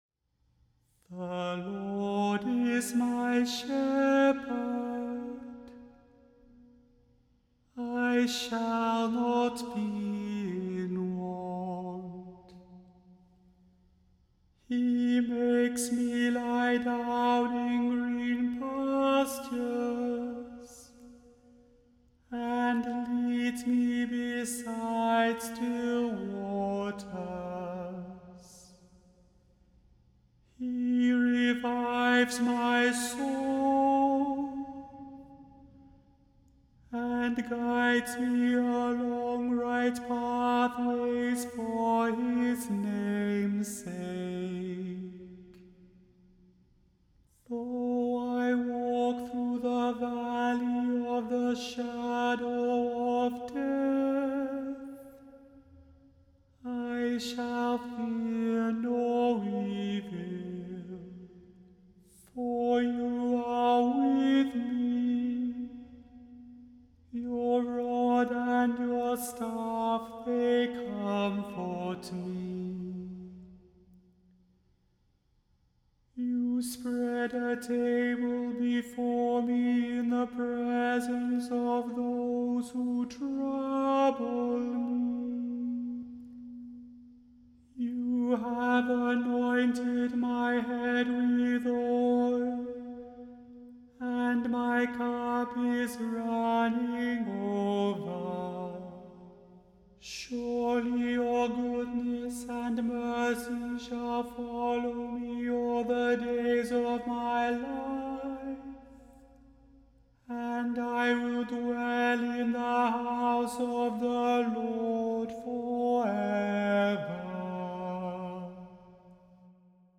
The Chant Project – Chant for Today (March 30) – Psalm 23